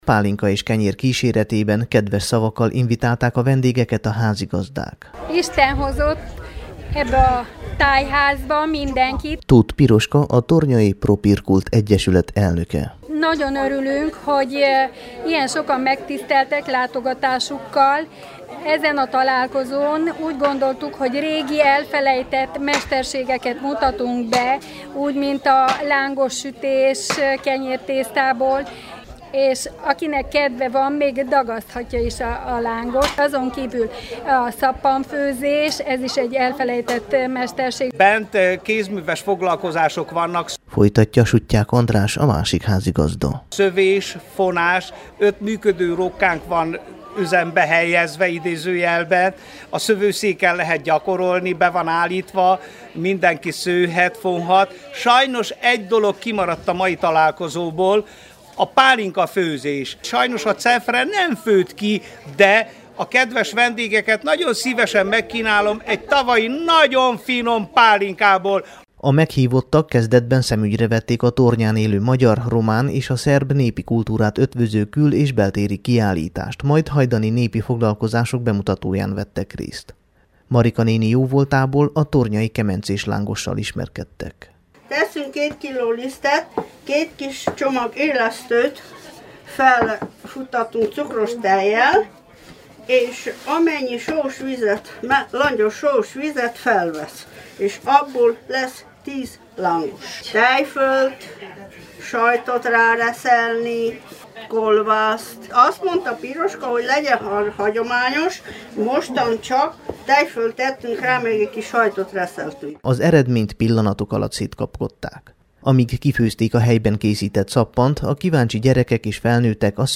Az aradi Media Spectator Stúdió a Duna TV Gazdakör című műsorának készített riportot a rendezvényről, az összeállítás rádióváltozata a Temesvári Rádió kulturális műsorában hangzik el